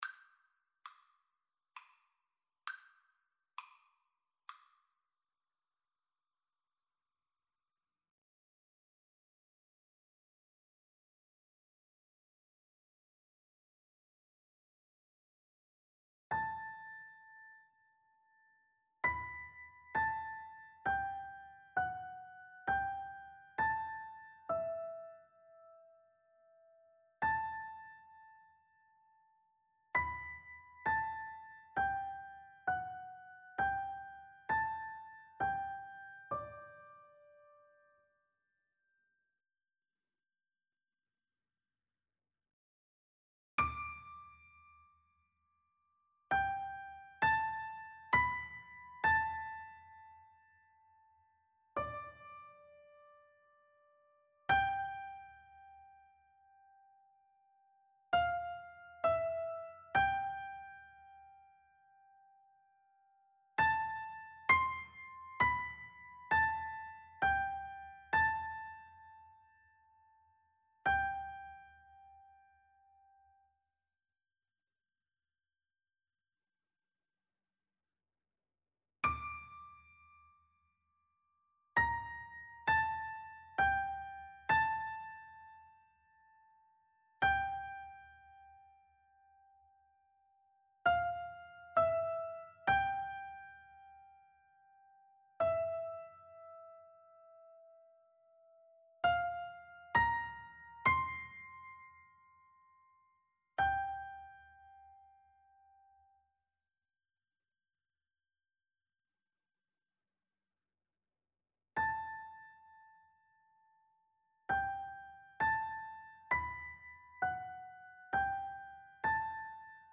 Lent et triste = 66
Classical (View more Classical Piano Duet Music)